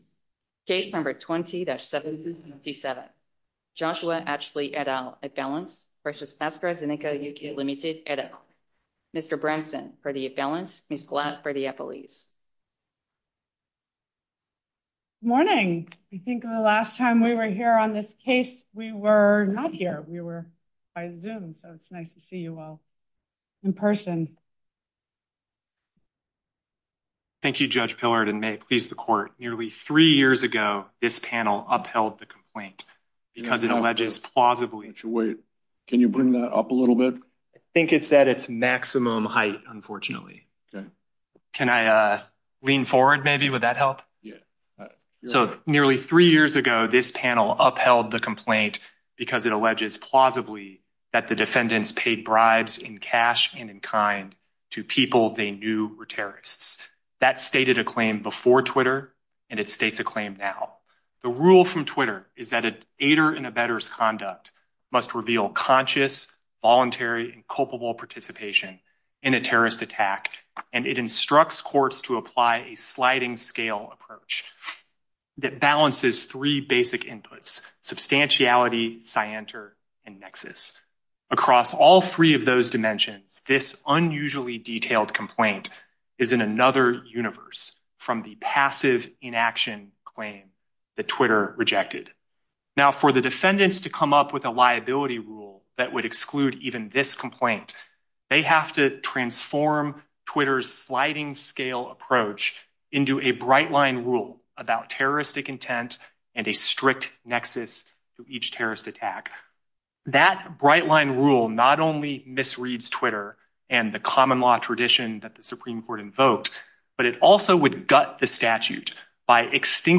Oral Arguments For The Court Of Appeals For The D.C. Circuit podcast